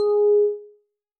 Techmino/media/sample/bell/12.ogg at e9bf6c3b58ba654497e657df73fac4e1db497d7b